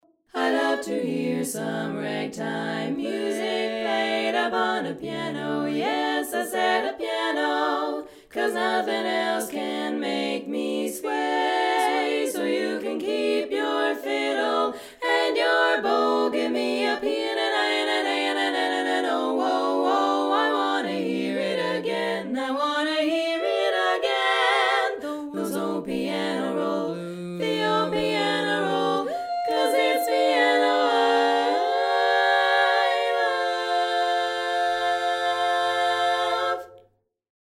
contest medley